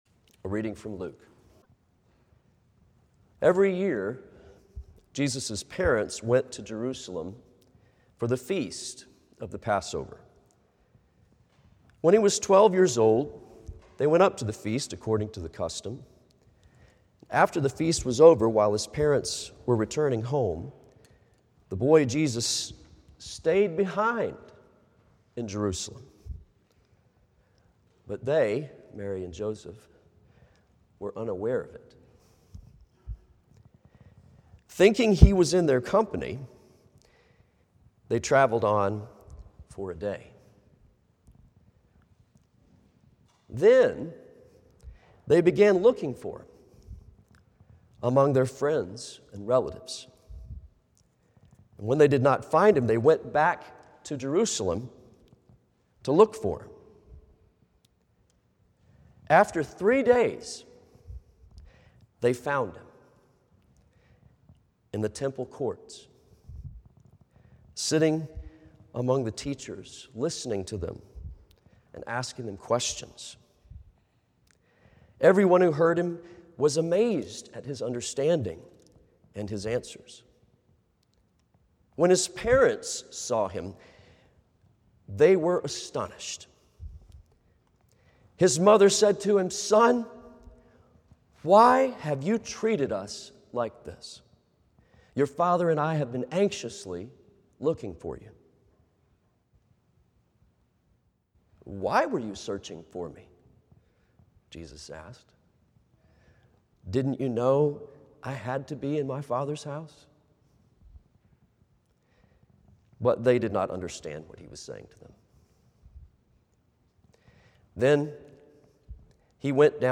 Morningside Presbyterian Church - Atlanta, GA: Sermons: Growing in Stature